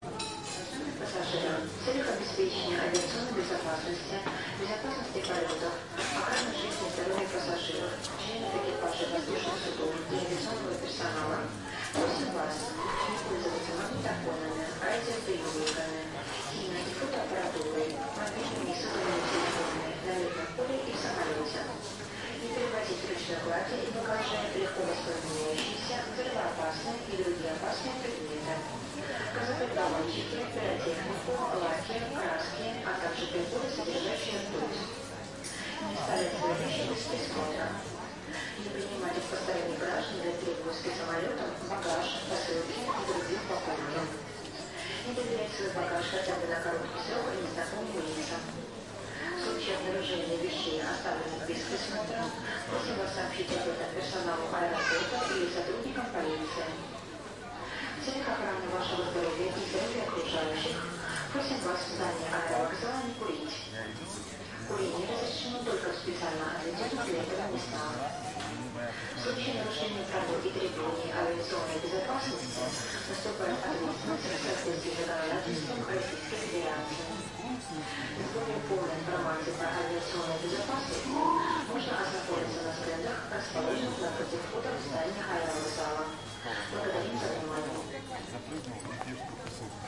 Здесь вы найдете четкие голоса дикторов, сигналы регистрации, звуки багажных тележек и другие элементы аэровокзальной среды.
Звук объявления информации диспетчером в аэропорту России (Мурманск) (01:30)